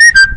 • Papagaio
parrot.wav